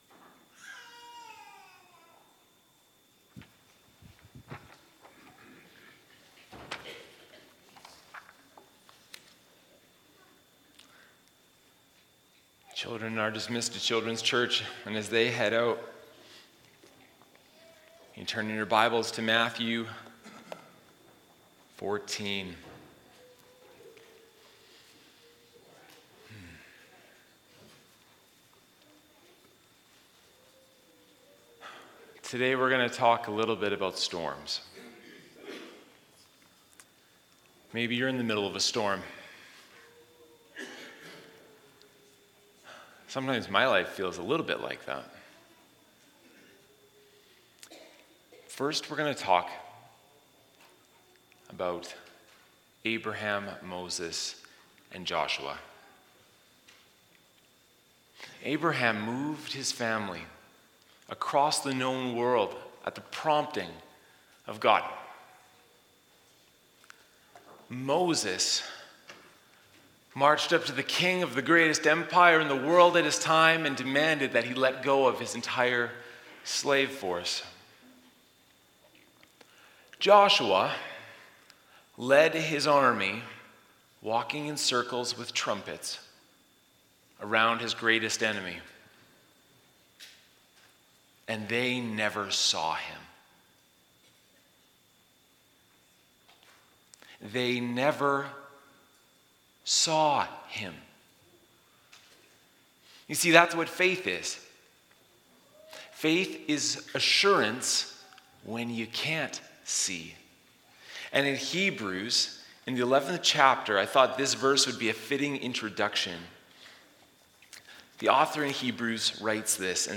Sermons | Bridgeway Community Church